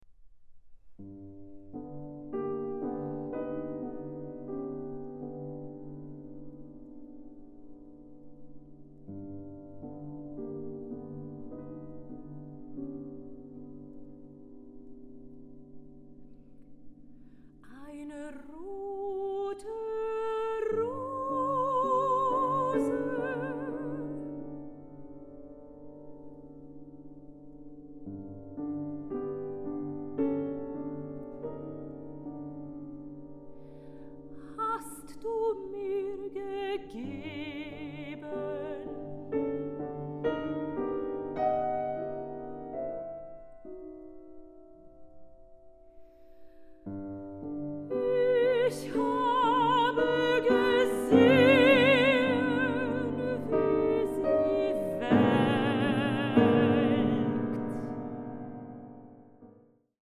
soprano
tenor
piano